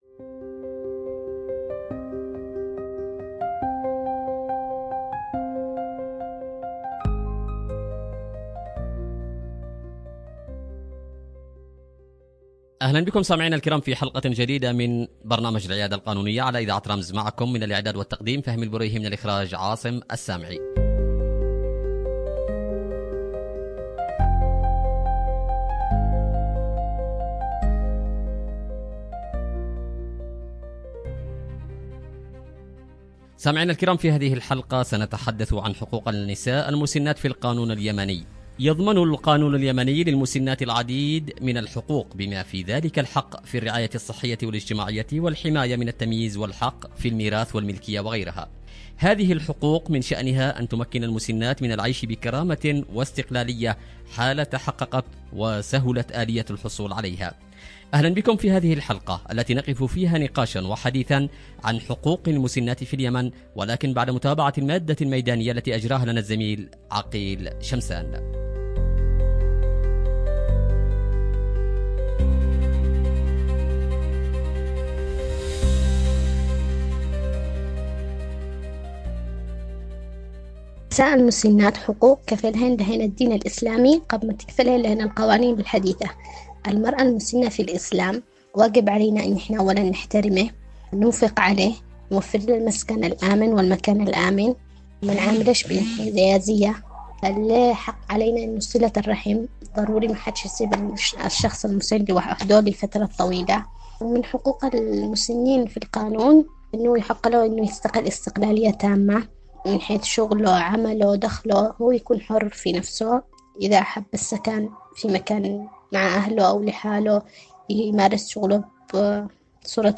نقاش معمّق
عبر إذاعة رمز